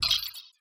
Hi Tech Alert 10.wav